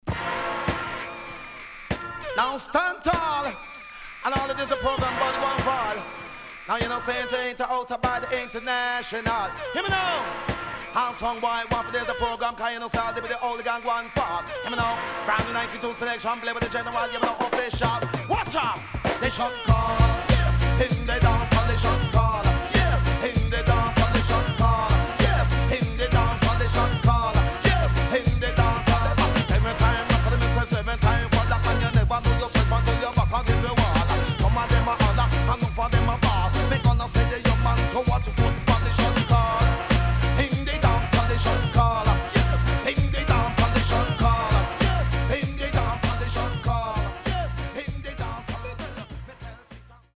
HOME > DISCO45 [DANCEHALL]
SIDE A:盤質は良好です。